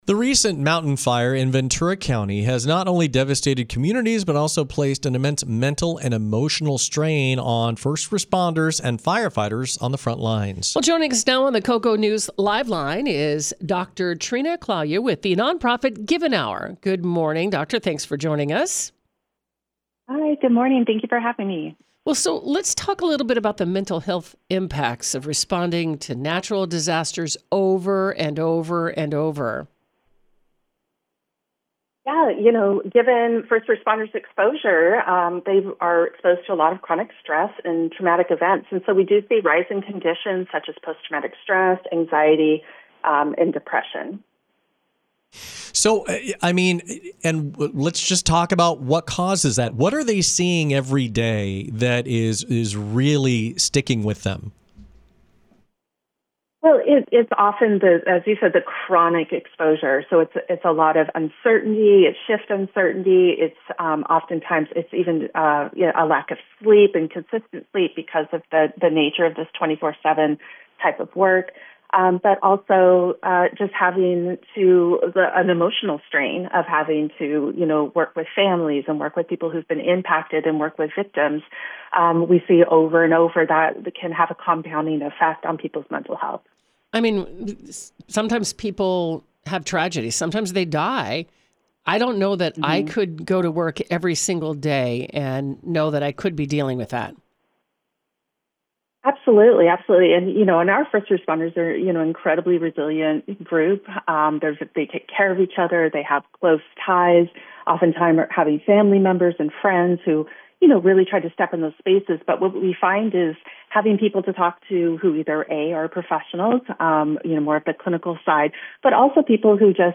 Supporting First Responders: A Conversation